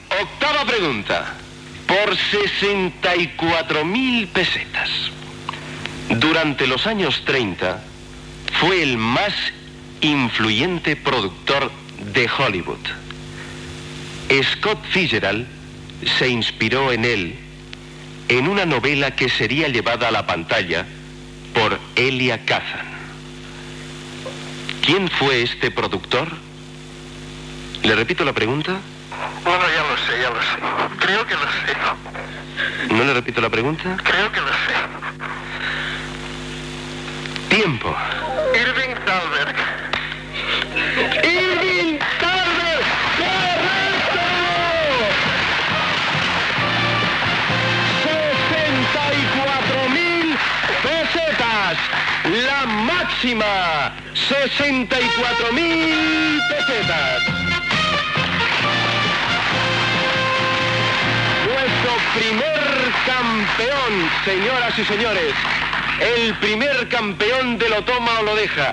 Pregunta a un oient que si encerta pot guanyar 64.000 pessetes
Entreteniment